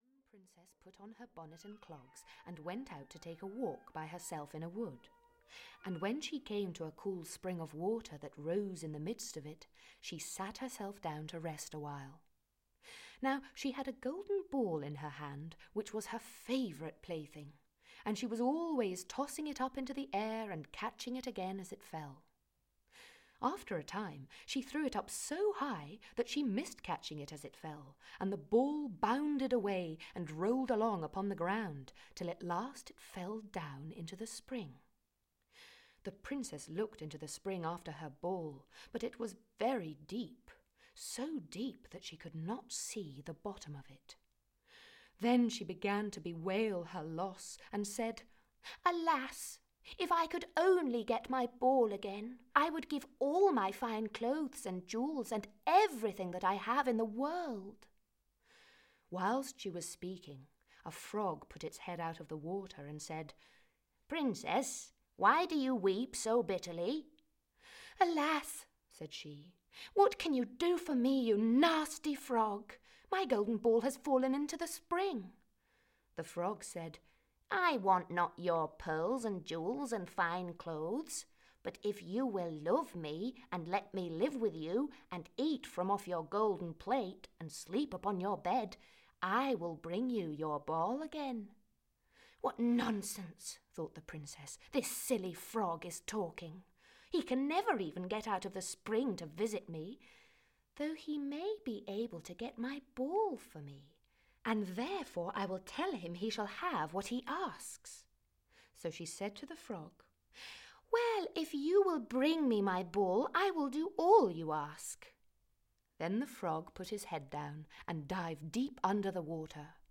Grimms’ Fairy Tales (EN) audiokniha
Ukázka z knihy